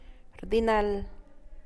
rdinal[rdinàall]